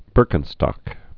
(bûrkĭn-stŏk)